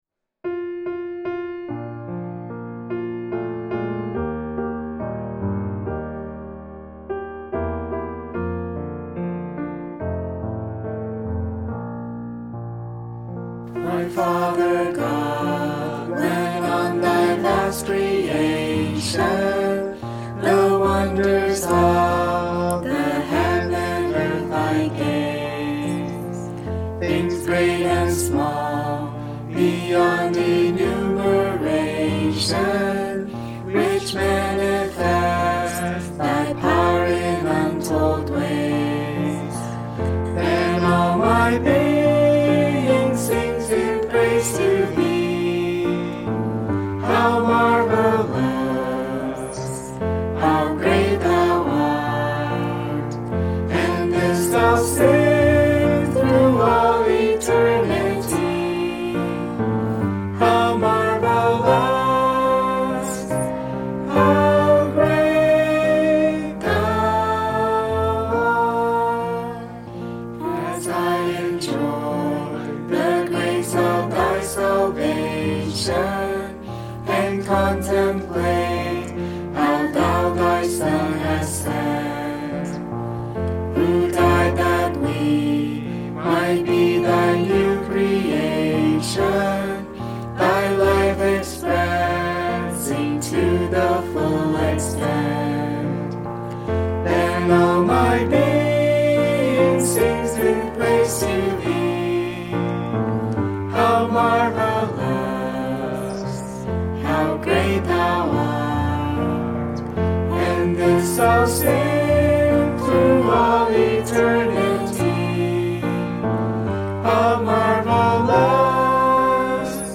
Swedish folk melody
Bb Major
e0017_sing.mp3